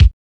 DRUM MACH K.wav